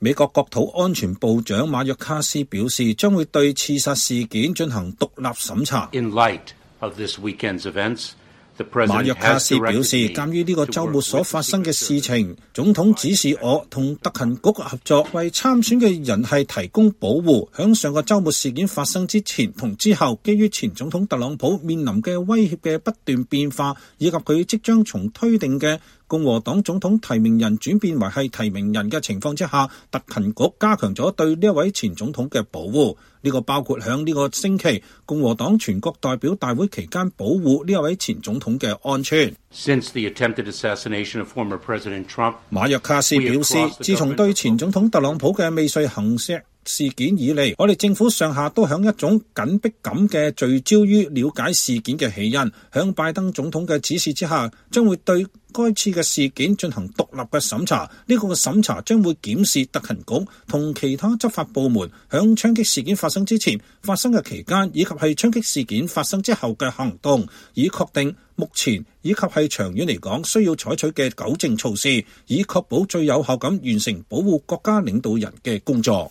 美國國土安全部長馬約卡斯於 2024 年 7 月 15 日在白宮舉行的新聞發布會上回答問題。